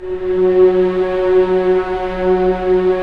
Index of /90_sSampleCDs/Roland LCDP13 String Sections/STR_Orchestral p/STR_Orch. p Slow